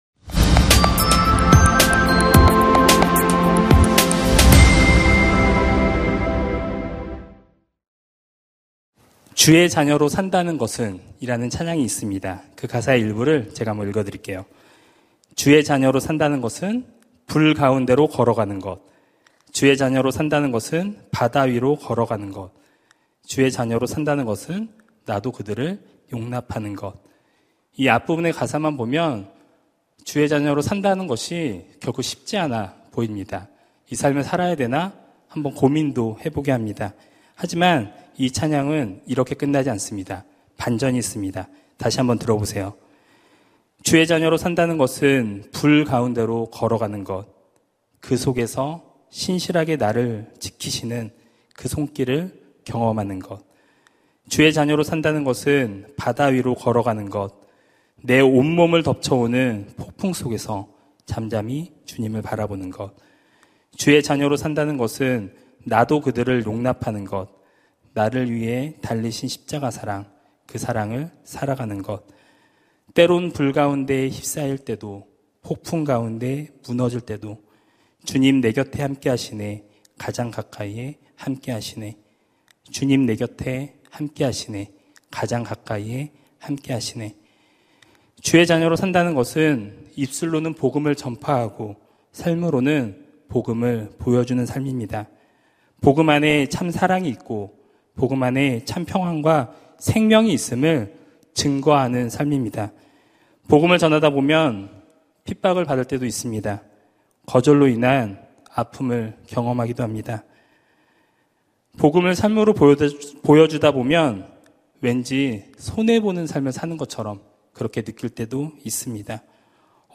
설교 : 금요심야기도회 (수지채플) 그거 아세요? 설교본문 : 민수기 23:7-12